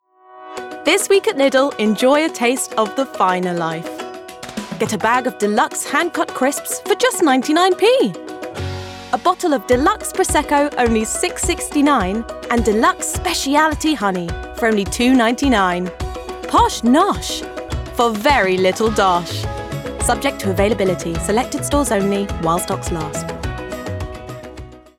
Voice Reel
Lidl - Bright, Upbeat